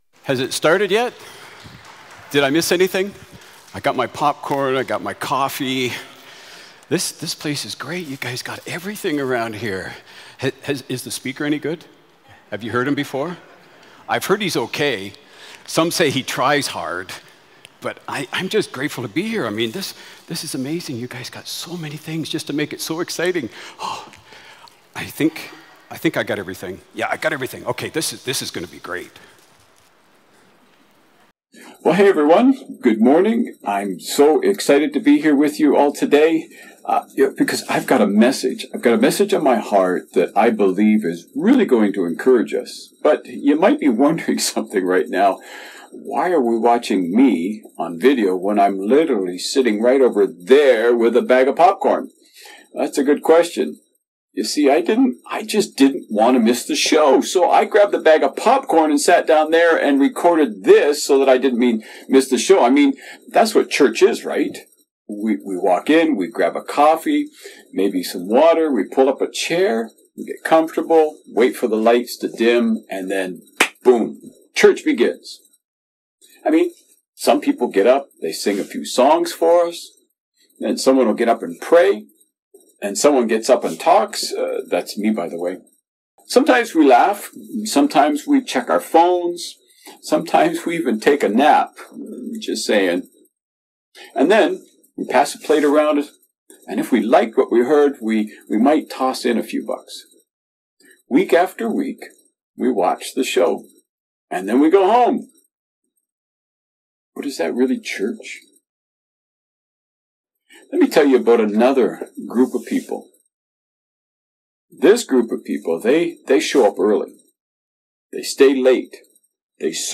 July-27-Sermon.mp3